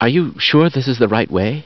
Download Half Life Right Way sound effect for free.